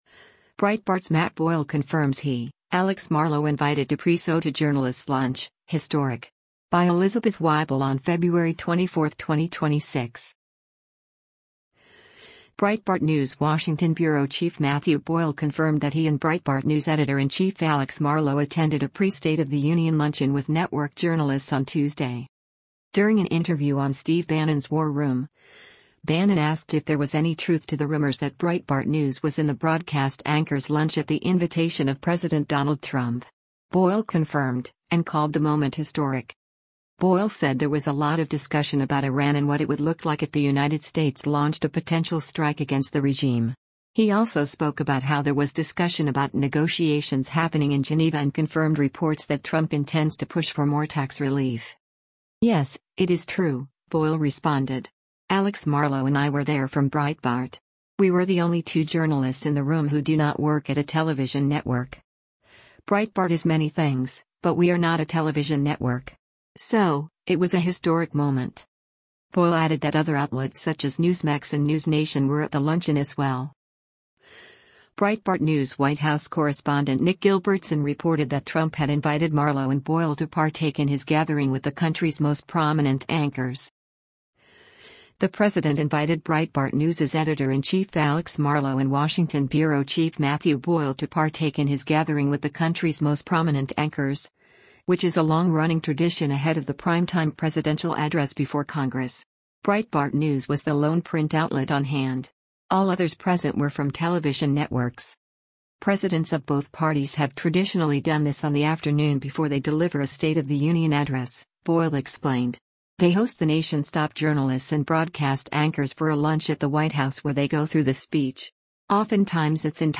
During an interview on Steve Bannon’s War Room, Bannon asked if there was “any truth” to the rumors that Breitbart News was “in the broadcast anchors lunch” at the invitation of President Donald Trump.